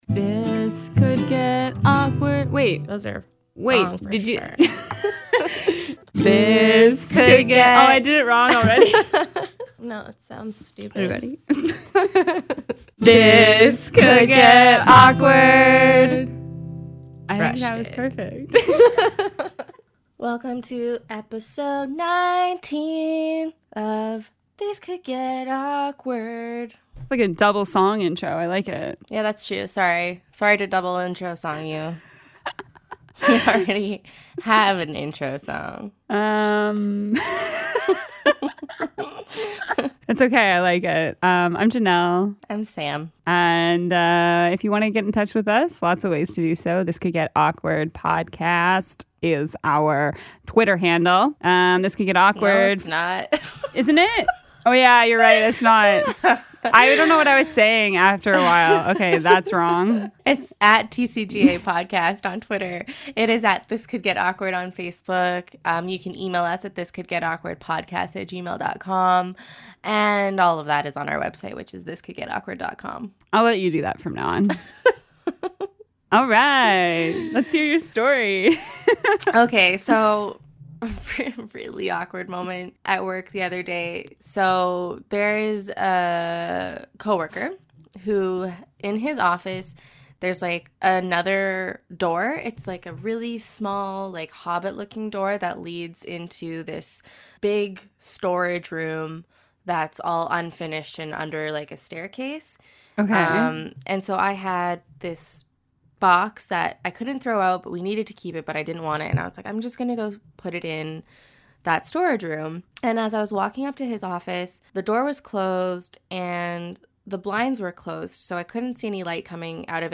This Could Get Awkward » Page 47 of 65 » A podcast by two awesome women.